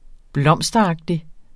Udtale [ ˈblʌmˀsdʌˌɑgdi ]